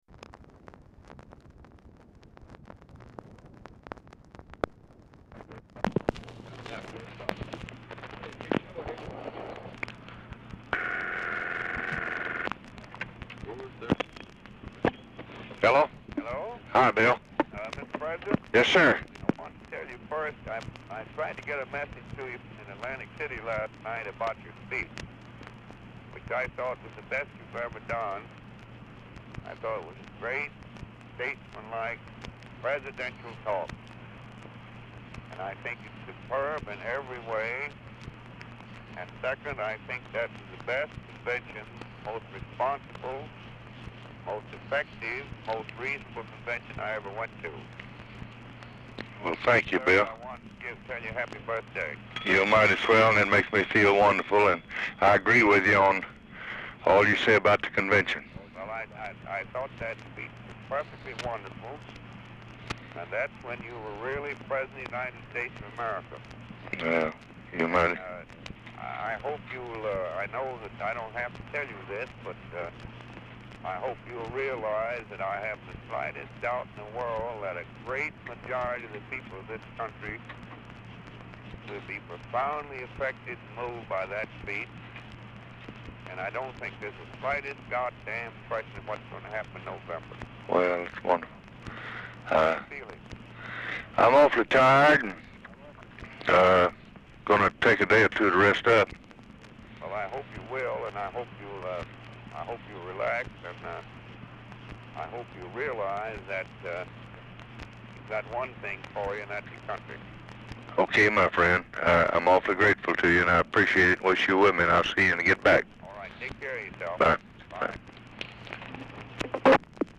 Telephone conversation # 5248, sound recording, LBJ and WILLIAM S. WHITE, 8/28/1964, 6:01PM | Discover LBJ
Format Dictation belt
Location Of Speaker 1 LBJ Ranch, near Stonewall, Texas